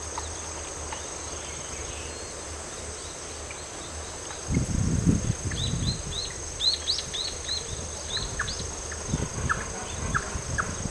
いつもは聞けないような声も聞けました。
今日の録音はココ（録音は10秒間、前半はおそらくクイナで最後の方にヒクイナの声）